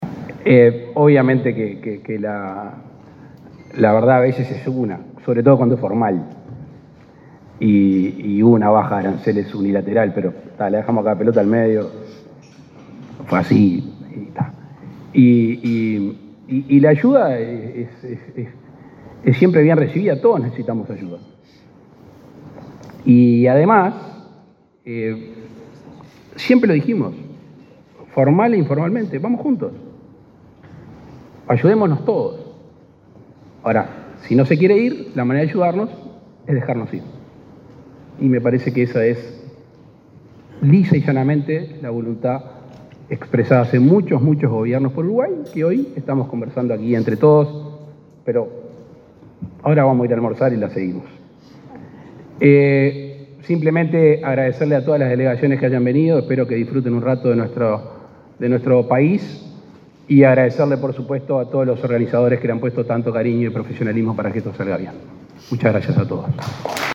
Palabras del presidente de Uruguay, Luis Lacalle Pou
El presidente de Uruguay, Luis Lacalle Pou, realizó el cierre de la Cumbre de Jefes de Estado del Mercosur y Estados Asociados, realizada este martes